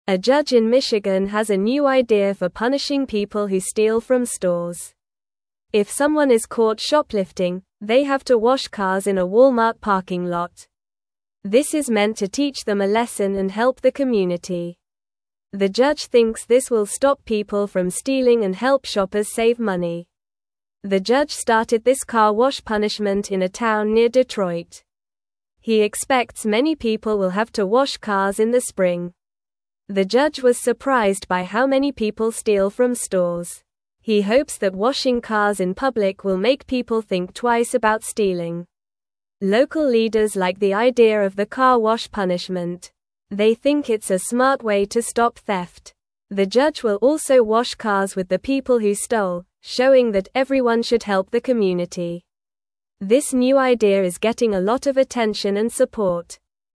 Normal
English-Newsroom-Lower-Intermediate-NORMAL-Reading-Judges-New-Plan-to-Stop-Store-Stealing.mp3